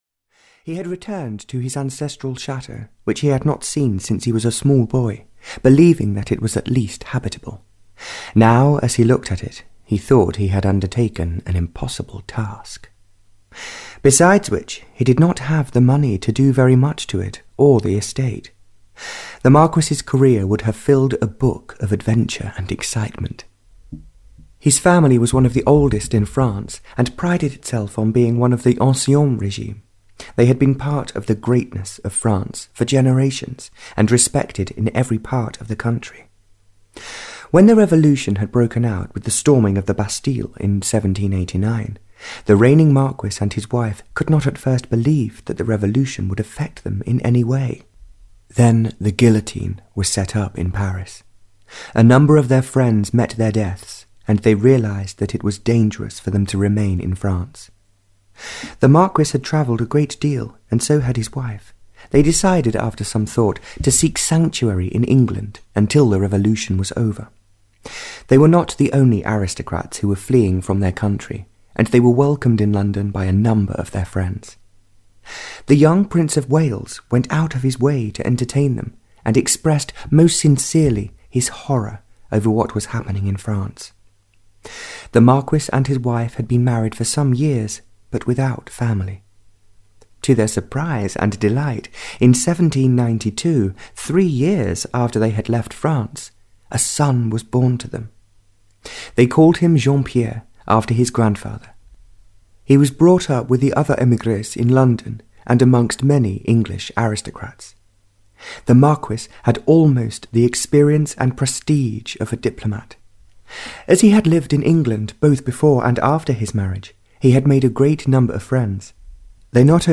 Saved by an Angel (Barbara Cartland’s Pink Collection 34) (EN) audiokniha
Ukázka z knihy